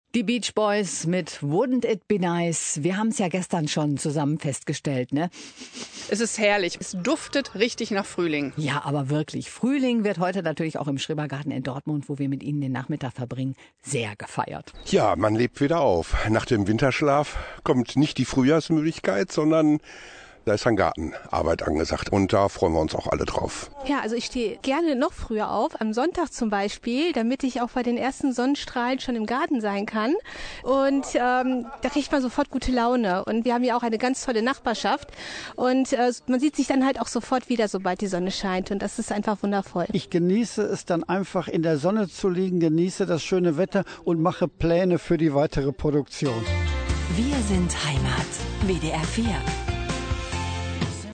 Gegen 16:40 lief dann ein Mitschnitt über den Sender, der während der Empfangsprobleme aufgezeichnet worden ist.